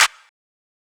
MZ Clap [Plugg Hi].wav